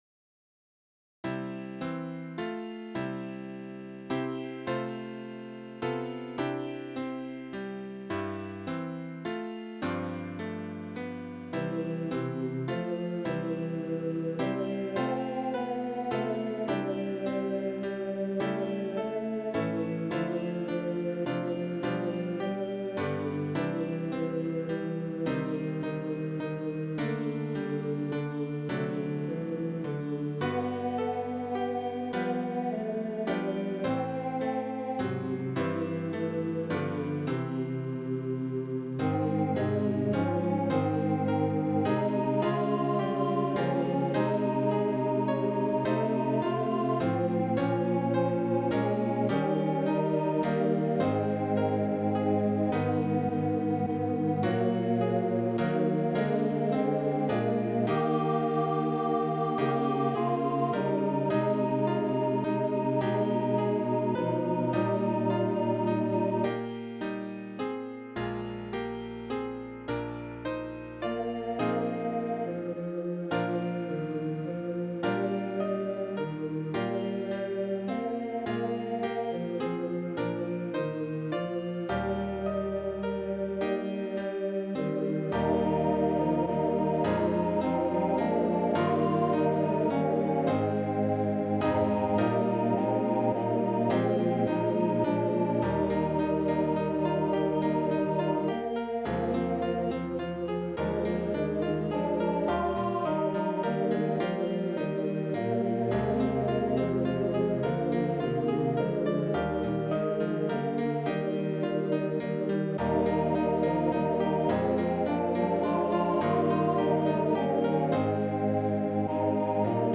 Voicing/Instrumentation: TTBB We also have other 56 arrangements of " Come, Follow Me ".